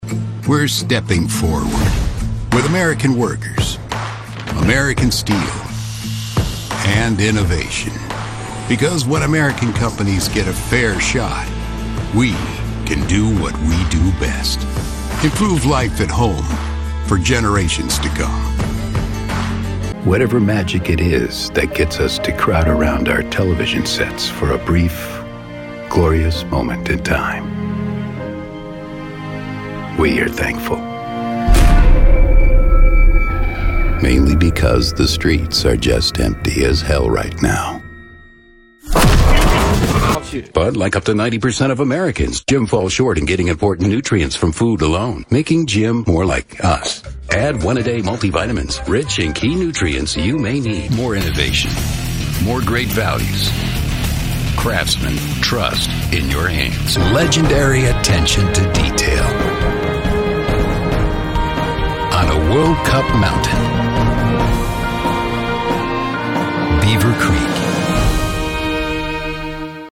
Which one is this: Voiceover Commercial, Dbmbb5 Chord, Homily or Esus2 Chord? Voiceover Commercial